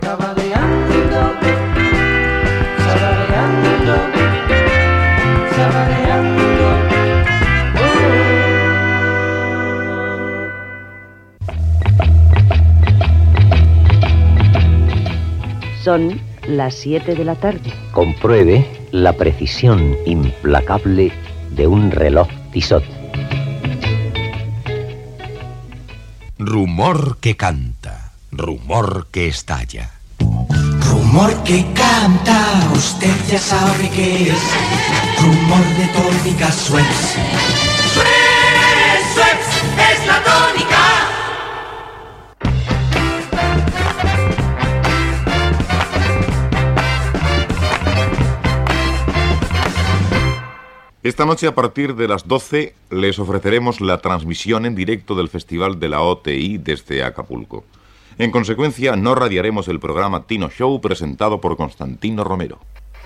Indicatiu cantat del programa, hora, publicitat i comunicat de canvi en la programació degut a la transmissió del Festival de la OTI.
Entreteniment